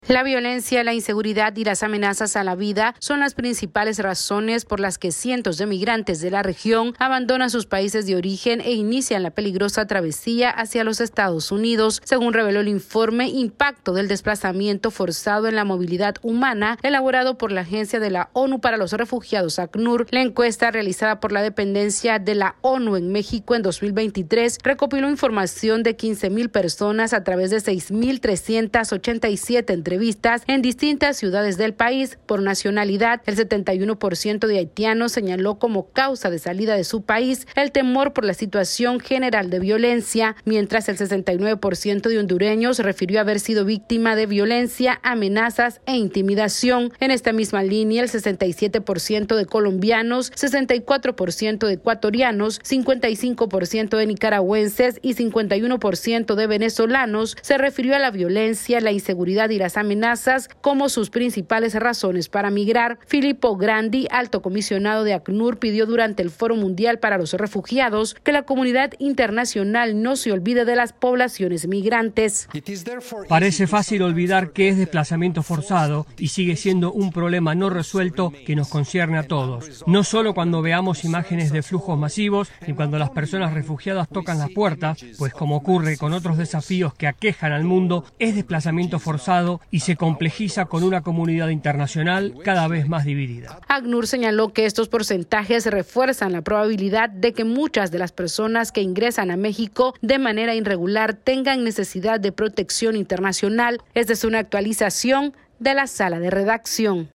AudioNoticias
Un reporte de la Agencia de la ONU para los Refugiados, ACNUR, revela que la mitad de los migrantes irregulares que llegan a México declaran salir de su país por la alta de seguridad. Esta es una actualización de nuestra Sala de Redacción...